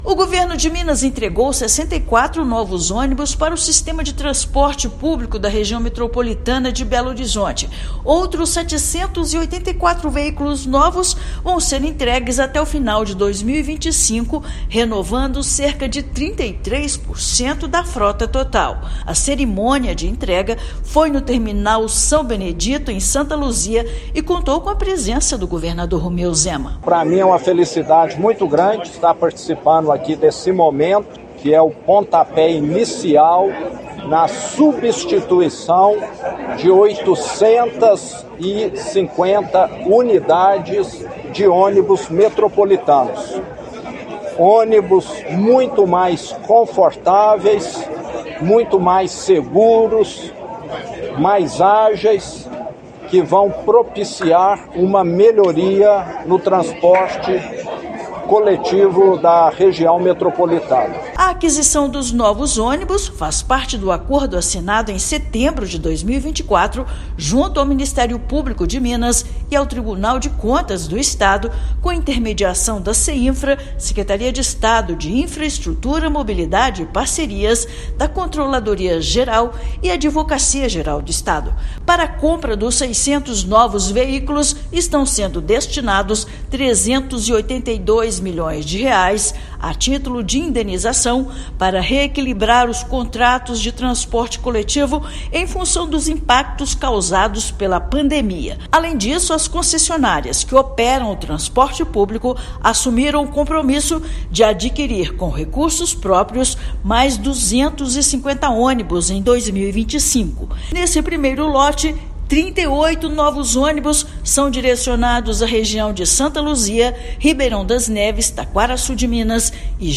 Até o final de 2025, 850 novos veículos vão entrar em operação em toda a Região Metropolitana de Belo Horizonte. Ouça matéria de rádio.